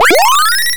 Powerup.mp3